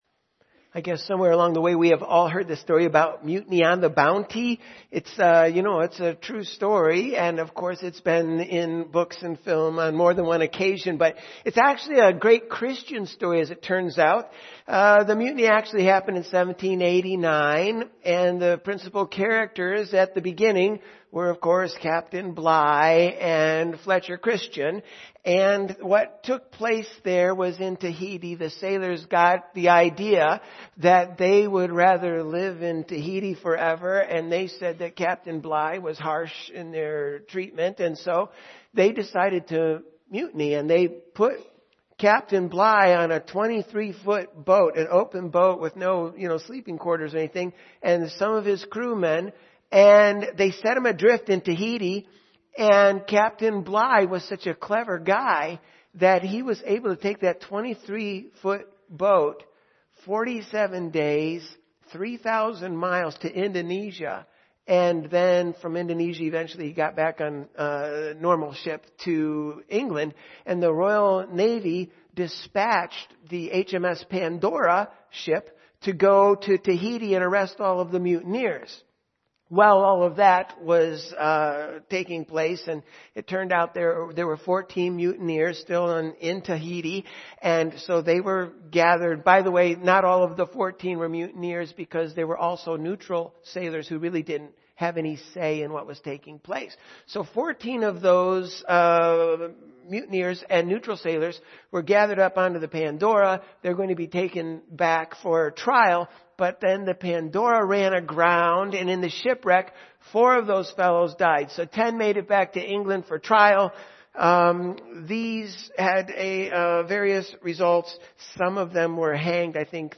Sunday Morning Service
Audio Sermons